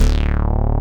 SYN_StBas D3.wav